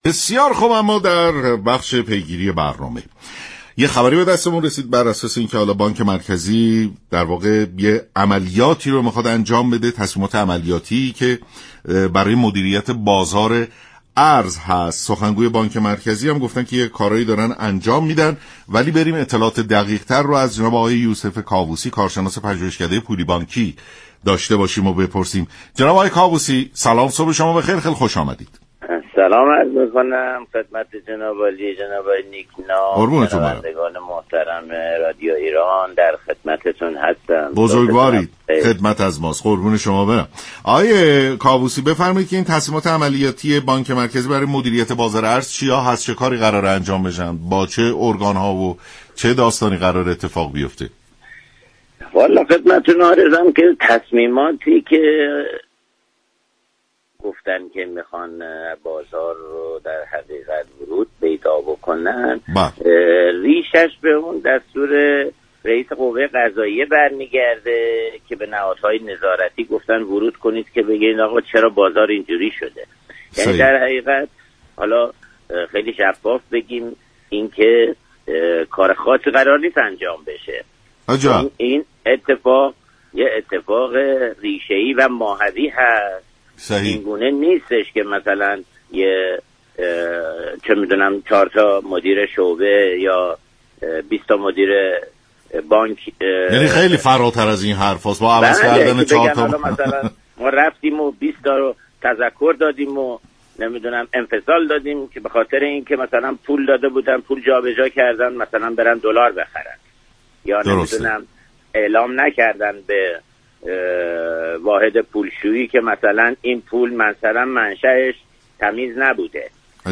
برنامه سلام‌صبح‌بخیر شنبه تا پنج‌شنبه ساعت 6:35 از رادیو ایران پخش می‌شود.